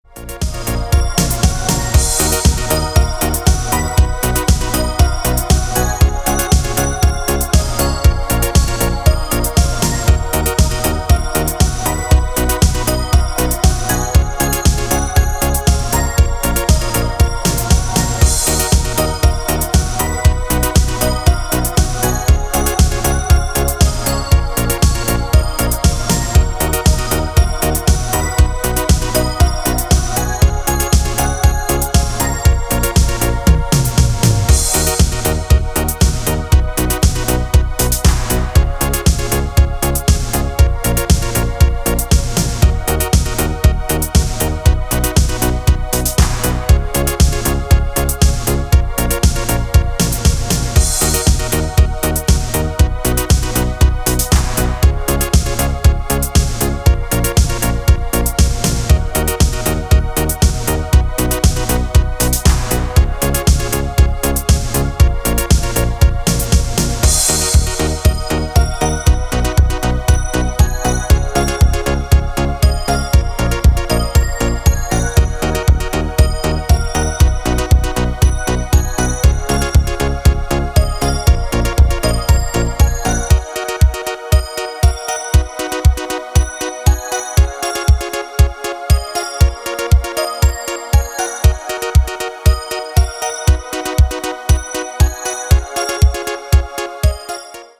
Рингтоны 80-х - 90-х